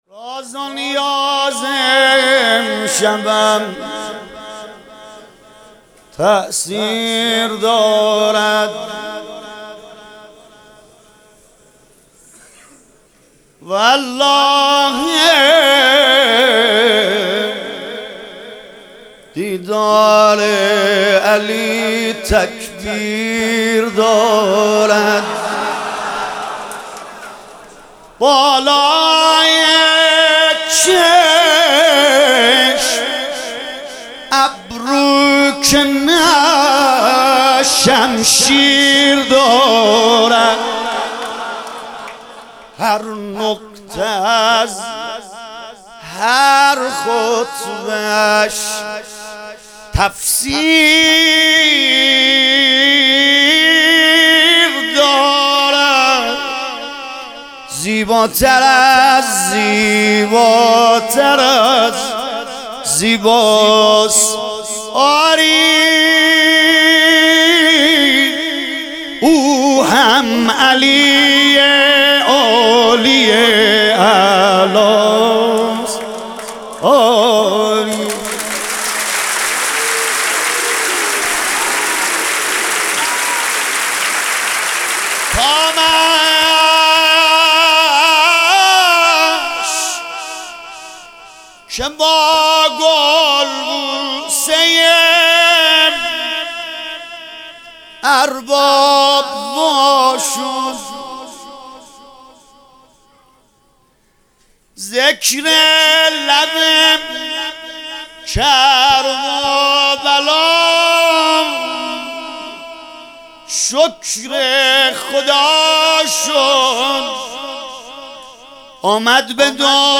مدح: راز و نیازِ امشبم تأثیر دارد